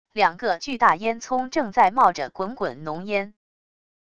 两个巨大烟囱正在冒着滚滚浓烟wav音频